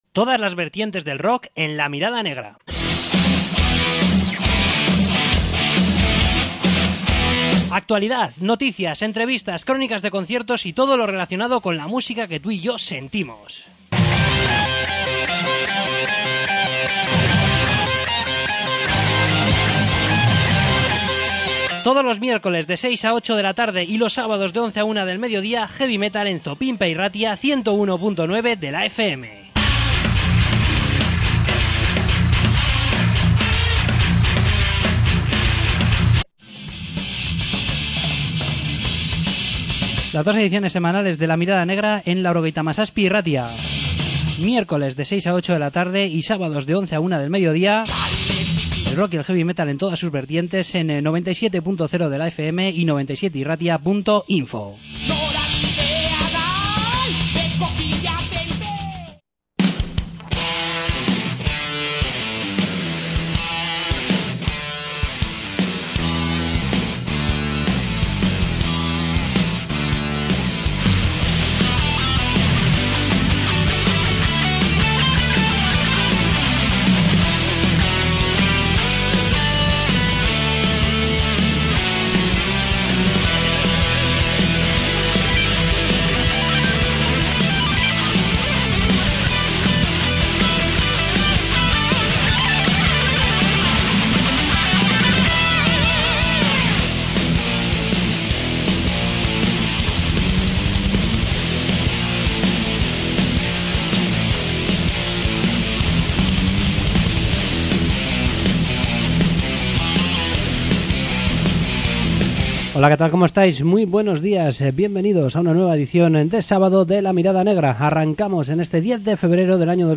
Entrevista con Elektrika